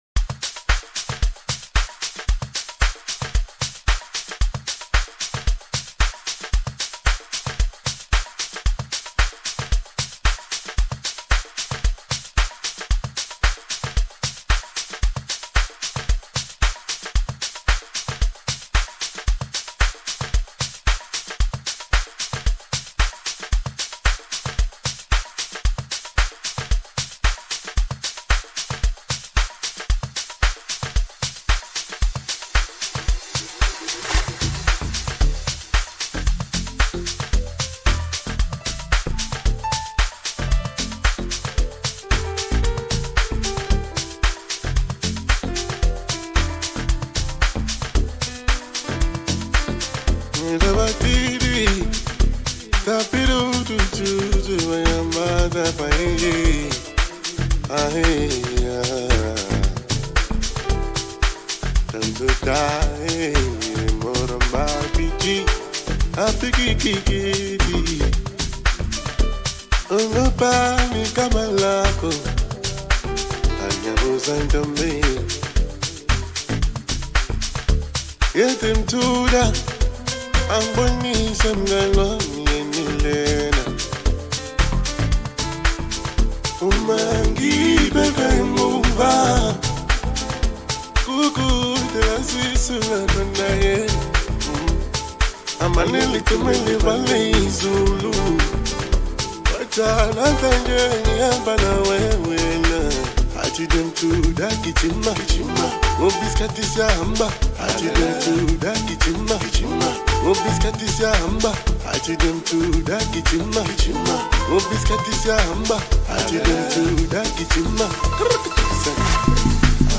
Private School Amapiano, sometimes called Maplanka.
vocals
smooth soulful production